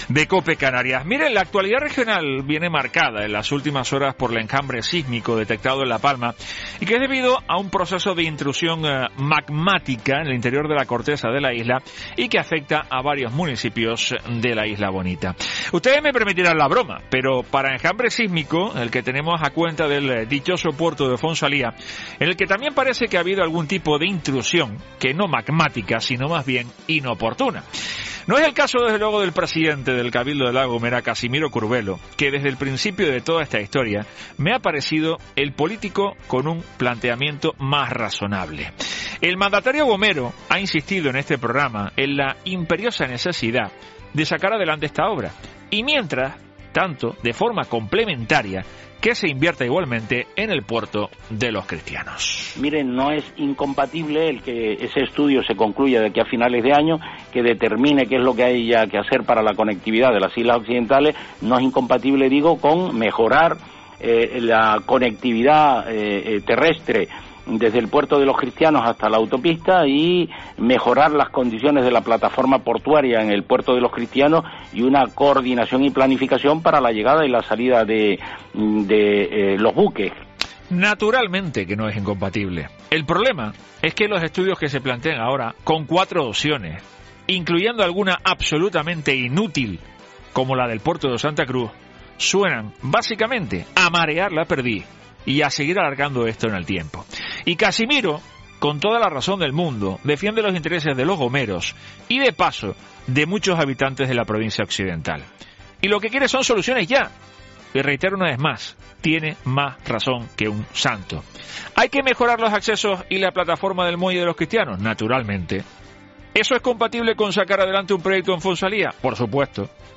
AUDIO: Escucha ya el editorial